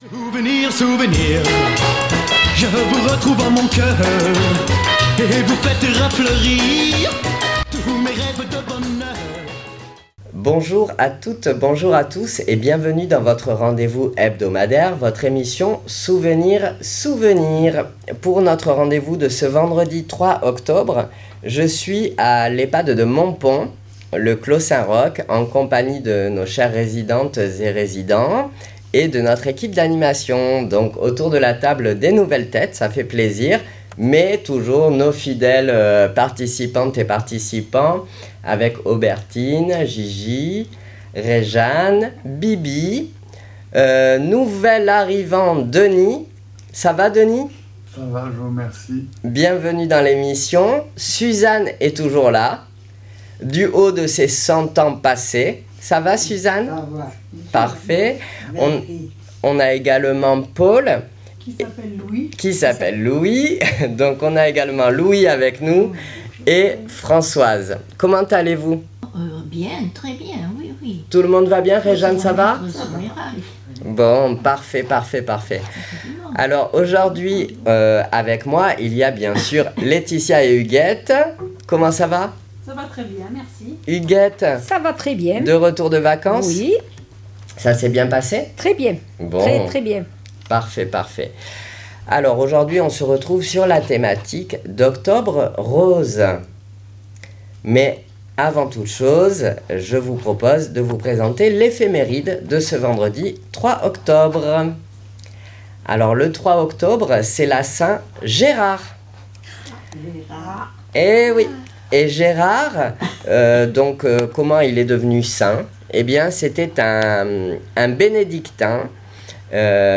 Souvenirs Souvenirs 05.10.25 à l'Ehpad de Montpon " Octobre rose "